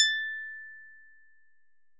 ギャグ・アニメ調（変な音）
ピーン！/閃き２